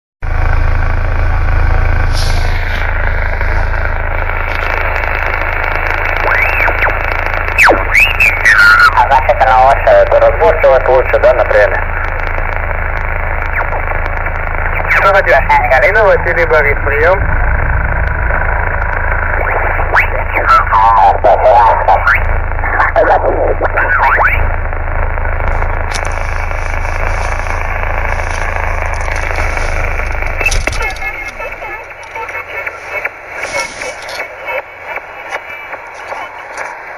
290 Кб 04.01.2010 18:28 Фрагмет приёма на ft-817 в авто.